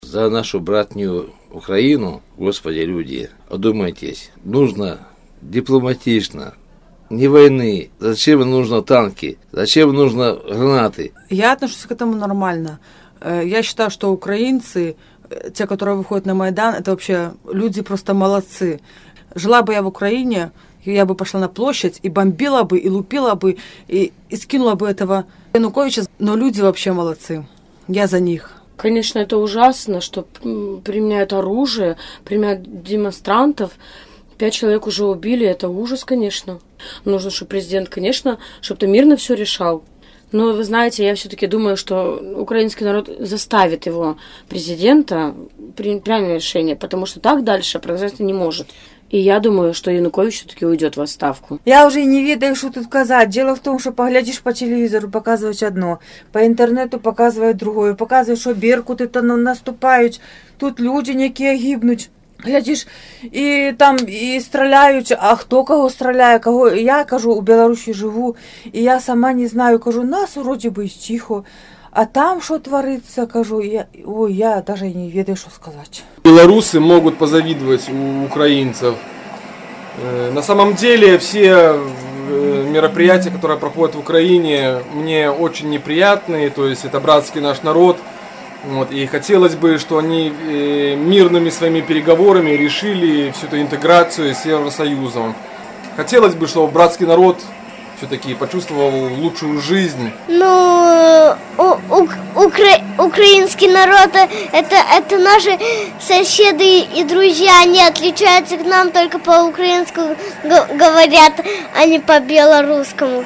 На такое пытаньне карэспандэнта «Свабоды» адказалі сёньня жыхары Берасьця.